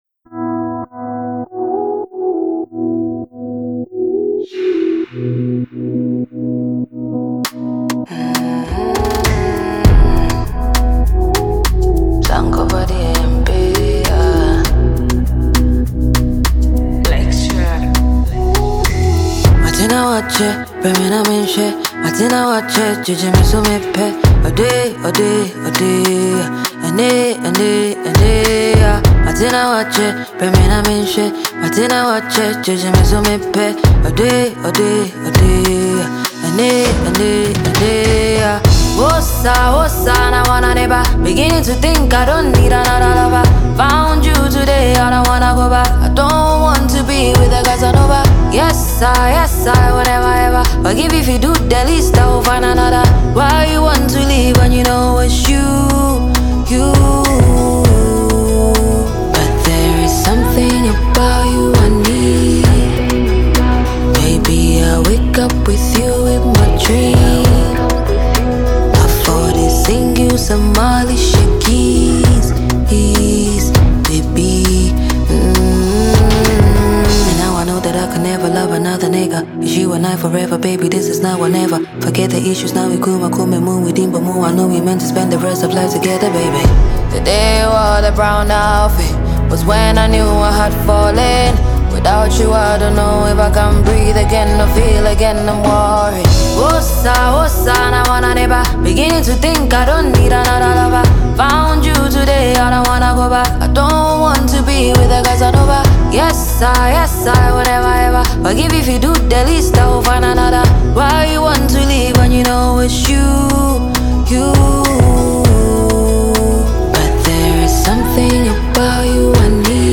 Ghanaian songstress
a soulful new single